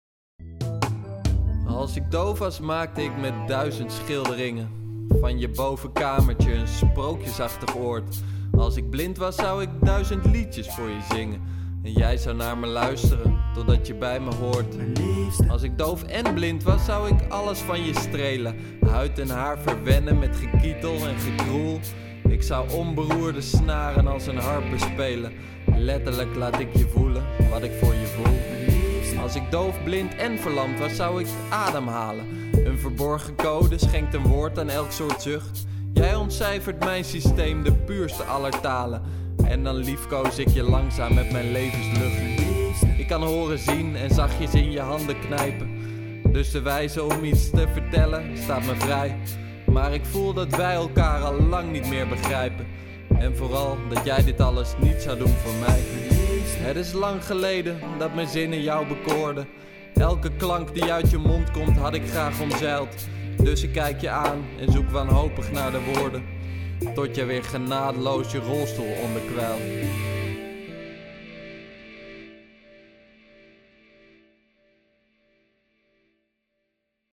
Echte liefde (gladde R&B)